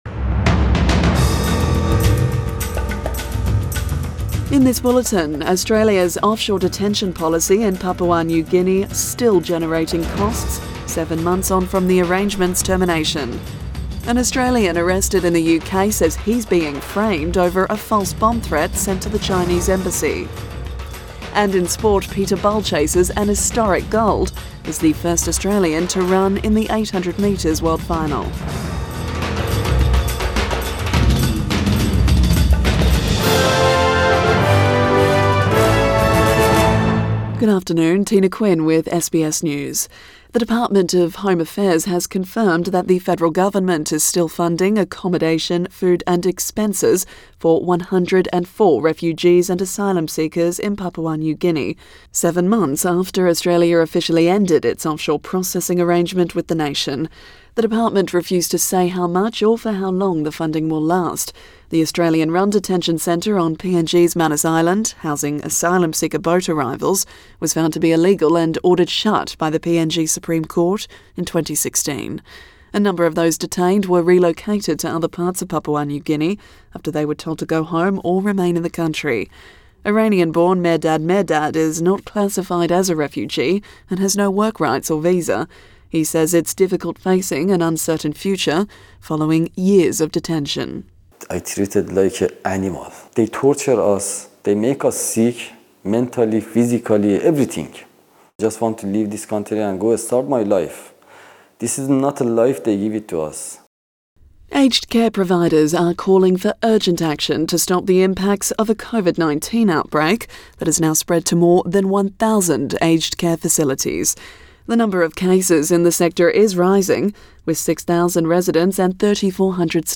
Midday bulletin 24 July 2022